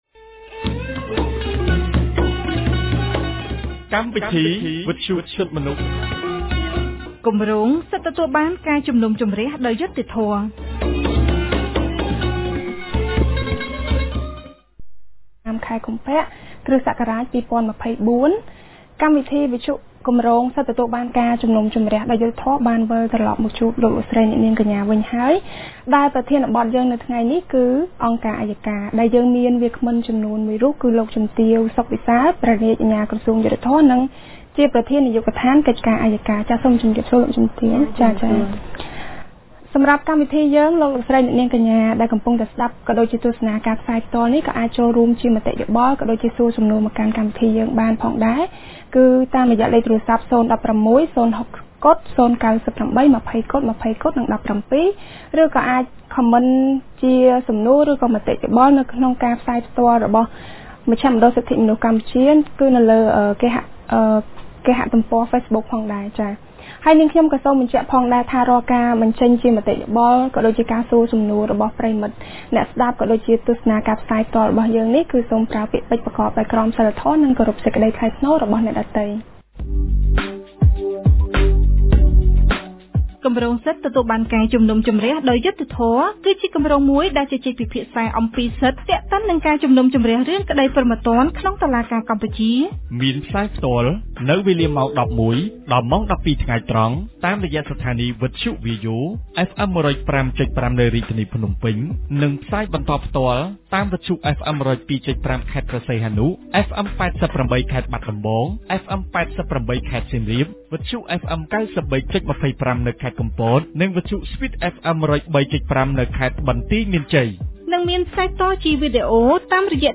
On Thursday 15 February 2024, CCHR’s Fair Trial Rights Project (FTRP) held a radio program with a topic “The Prosecution Office”. Honorable speaker for this program was H.E. SOK Wisal Prosecutor and Director of Prosecution Affair Department.